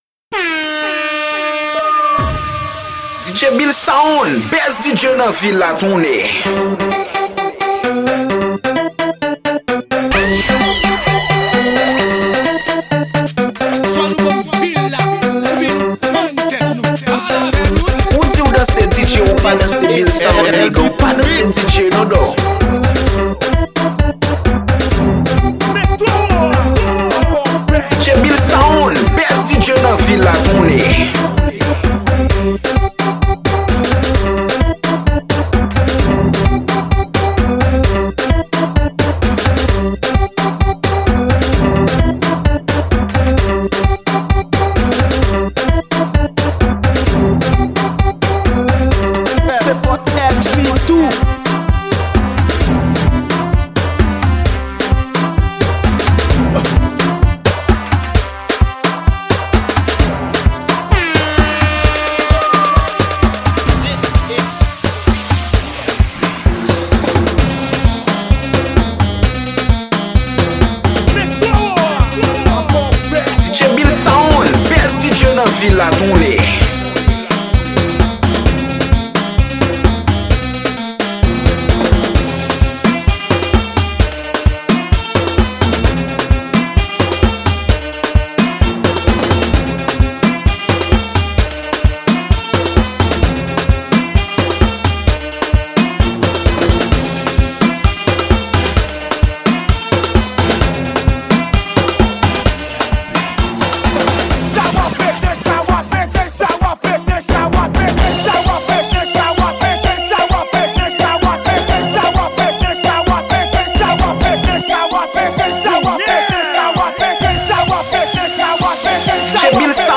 Genre: AFRO-REMIX.